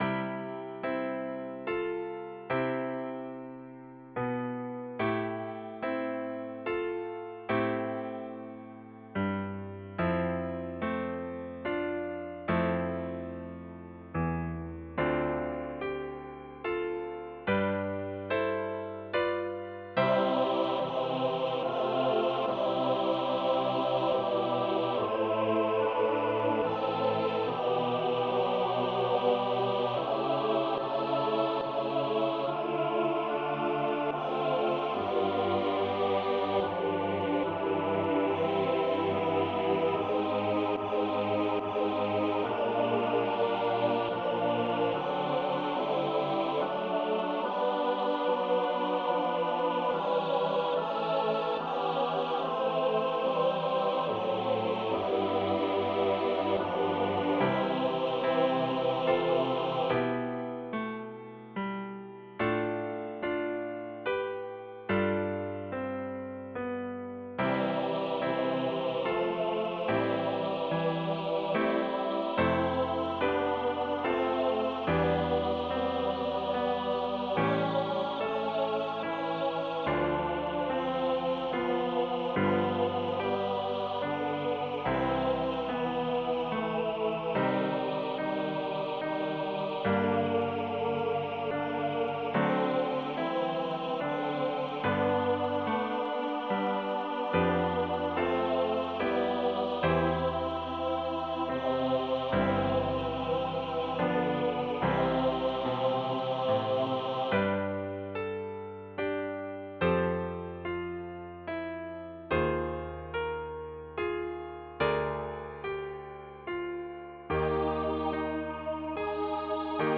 Hymn arrangement for SATB Choir and Piano with Congregation (optional) joining in on the last verse.
Voicing/Instrumentation: SATB We also have other 61 arrangements of " Come, Follow Me ".
Choir with Congregation together in certain spots